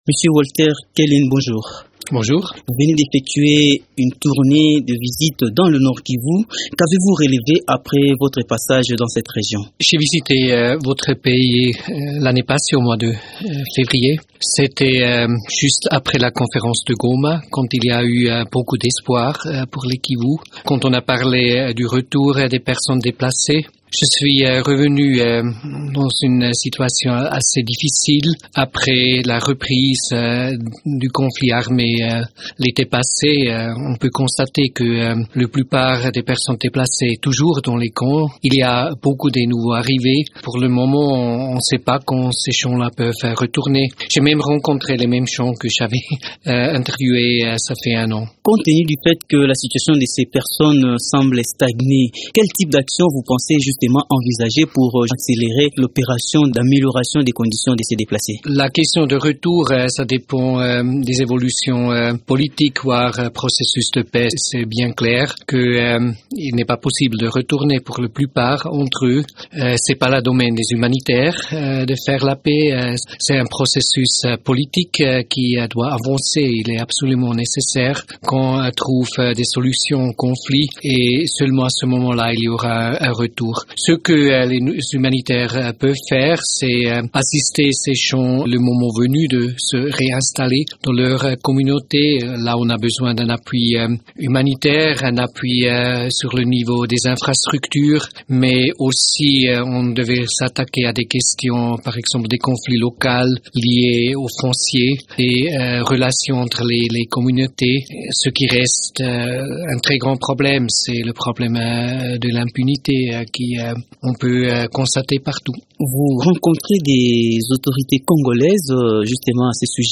Walter Kalin s’est rendu mardi dernier dans le Nord Kivu où il a visité les camps des personnes déplacées, suite au conflit armé dans cette région. Dans cet entretien